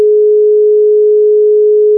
422.5 Hz tone Date 3 February 2004
Tone-422.5-Hz.ogg